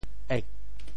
潮州拼音“êg4”的详细信息